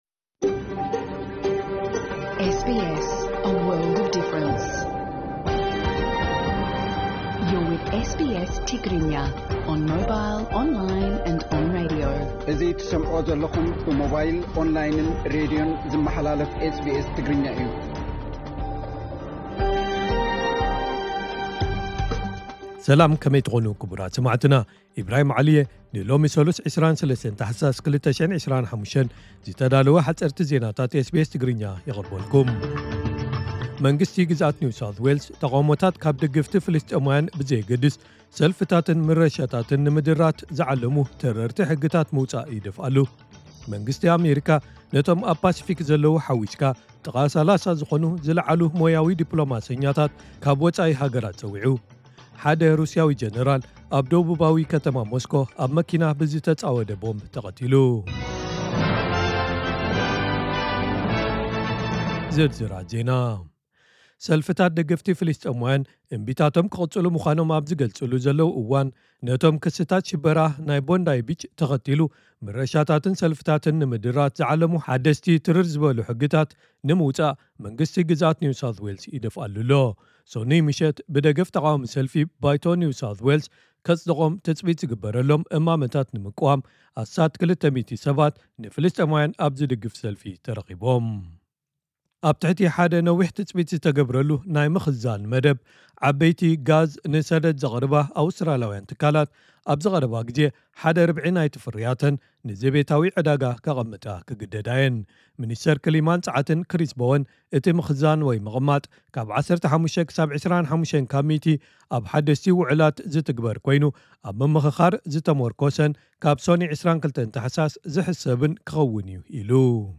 ሓጸርቲ ዜናታት ኤስ ቢ ኤስ ትግርኛ (23 ታሕሳስ 2025)